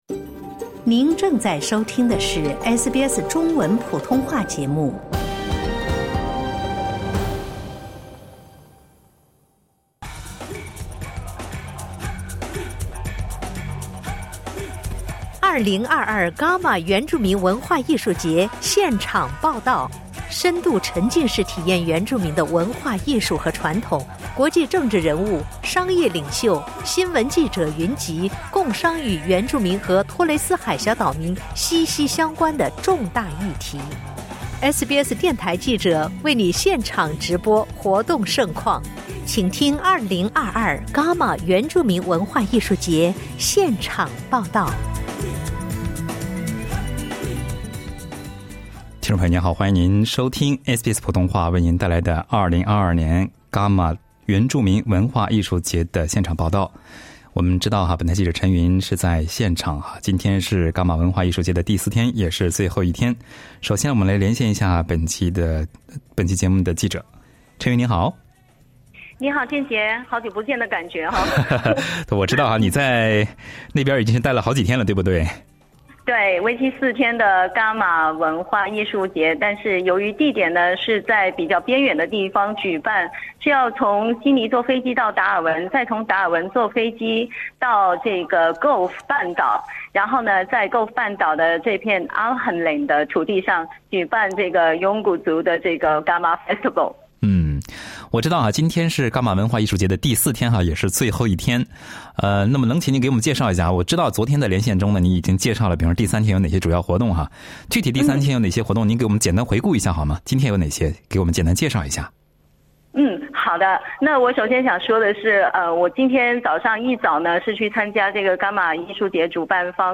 【伽马文化艺术节第四天】特派记者现场报道